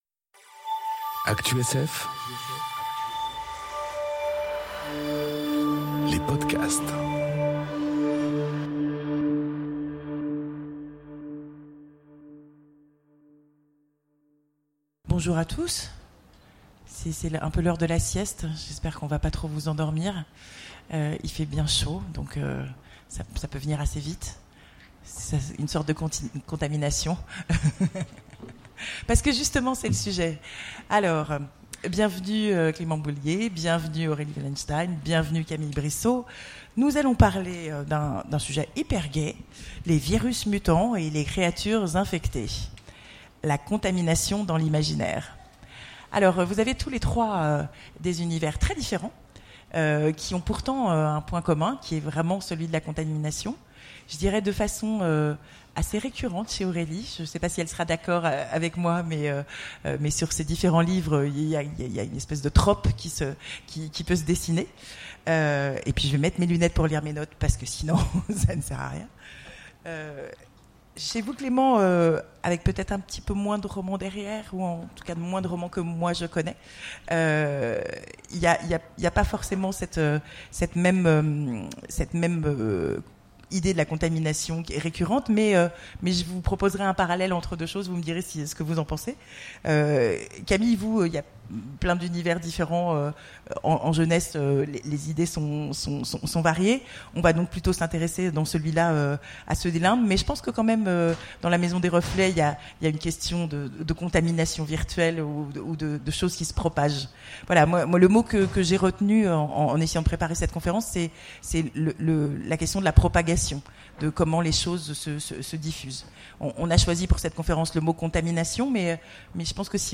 Conférence Virus mutants, créatures infectées : la contamination dans l'imaginaire enregistrée aux Imaginales 2018